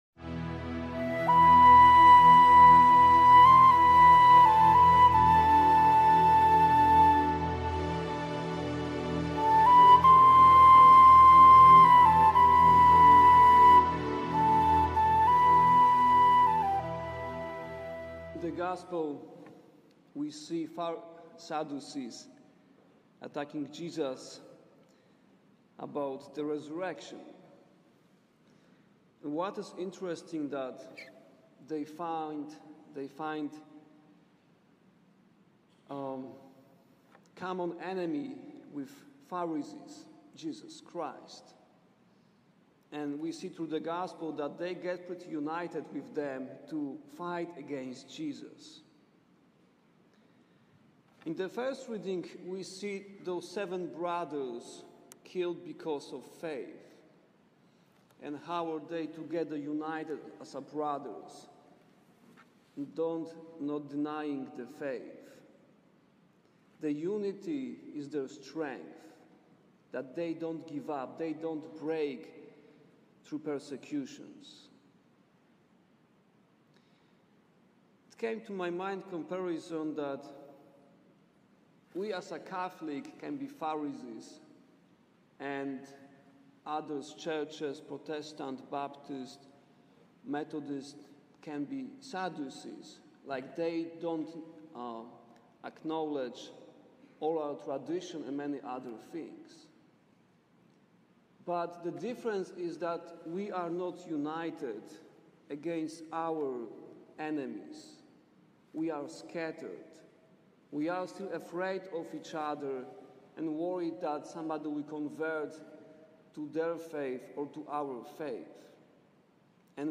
homily about unity.
homily-for-the-32-sunday-unity.mp3